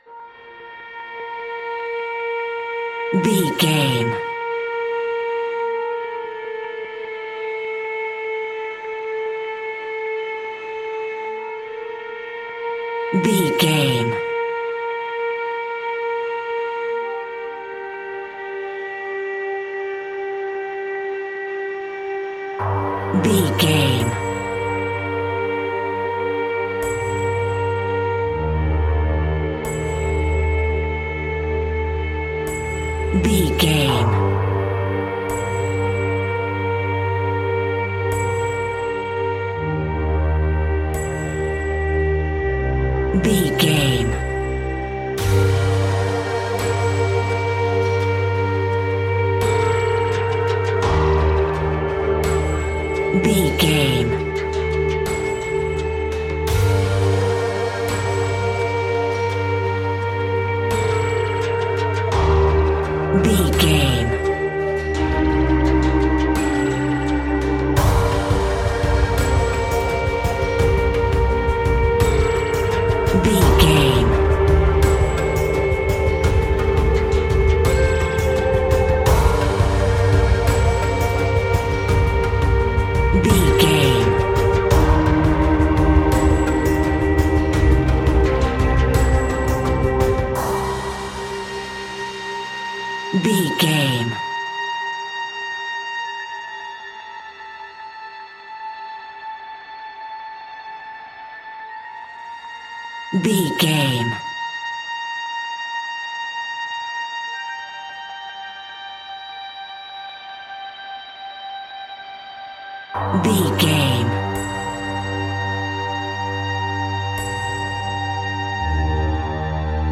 Aeolian/Minor
ominous
dark
haunting
eerie
synthesizer
drum machine
ticking
electronic music
electronic instrumentals
Horror Synths